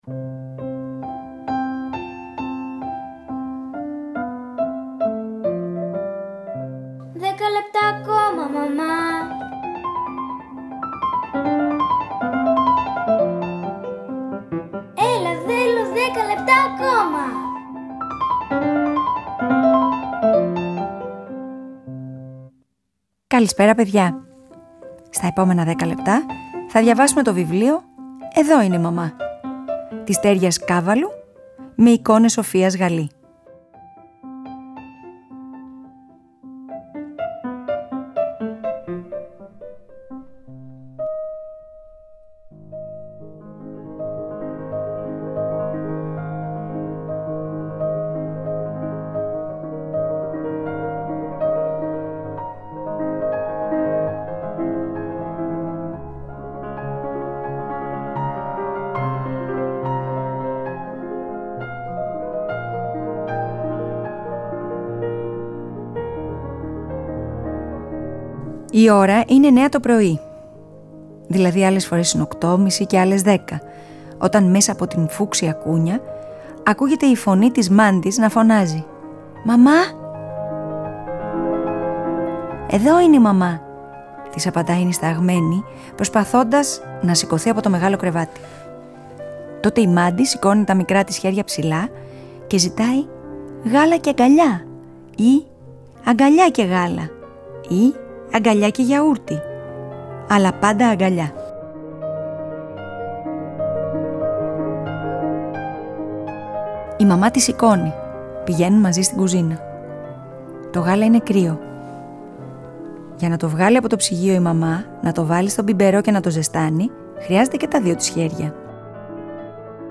Σήμερα στις 20:00, στο Τρίτο Πρόγραμμα 90,9, διαβάσαμε το βιβλίο “Εδώ είναι η μαμά” της Στέργας Καββάλου, με εικόνες Σοφίας Γαλή, από τις εκδόσεις Ellinoekdotiki Publishing – Ελληνοεκδοτική Εκδόσεις
Frederic Chopin, Berceuse In D Flat, Op.57